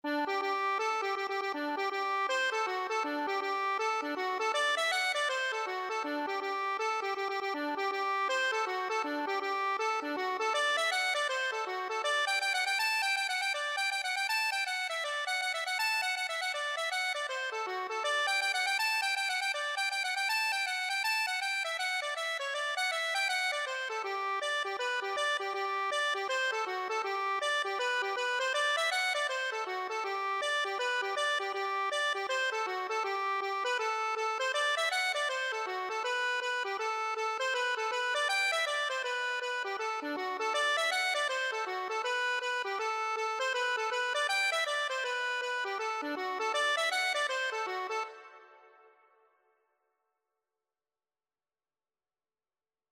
G major (Sounding Pitch) (View more G major Music for Accordion )
4/4 (View more 4/4 Music)
Accordion  (View more Intermediate Accordion Music)
Traditional (View more Traditional Accordion Music)
kiss_the_maid_ON1308_ACC.mp3